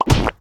hero_death_hitcam.ogg